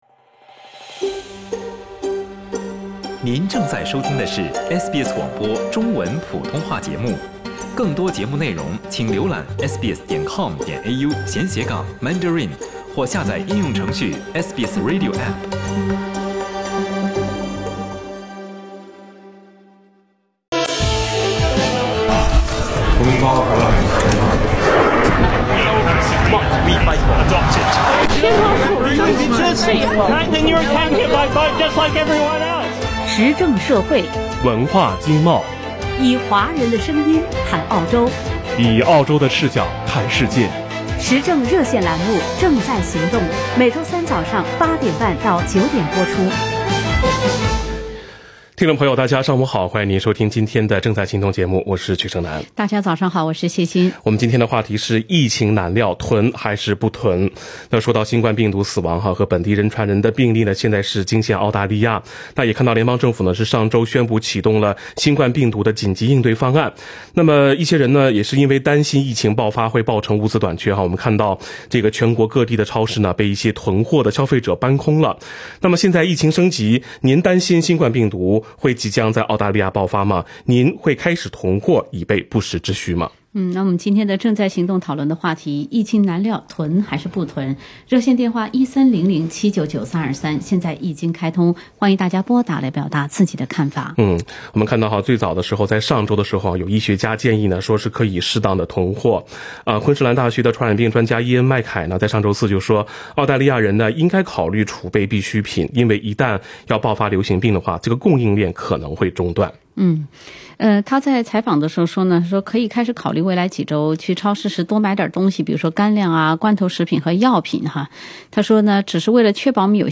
听众讨论：疫情当前，囤不囤？